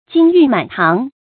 金玉满堂 jīn yù mǎn táng 成语解释 金、玉：黄金和美玉。
成语繁体 金玉滿堂 成语简拼 jymt 成语注音 ㄐㄧㄣ ㄧㄩˋ ㄇㄢˇ ㄊㄤˊ 常用程度 常用成语 感情色彩 中性成语 成语用法 偏正式；作谓语、宾语；形容财产很多 成语结构 偏正式成语 产生年代 古代成语 近 义 词 堆金积玉 反 义 词 家徒四壁 成语例子 真长（刘惔）可谓 金玉满堂 。